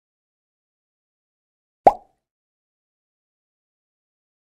match-found.ZjaIYfyn.mp3